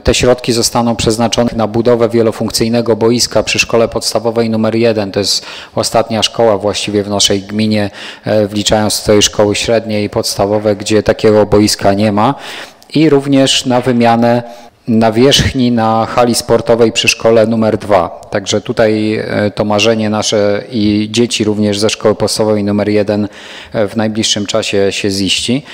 Niemal 700 tysięcy złotych otrzyma samorząd Opatowa na poprawę szkolnej bazy sportowej. Mówi burmistrz Grzegorz Gajewski: